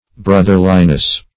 Brotherliness \Broth"er*li*ness\, n. The state or quality of being brotherly.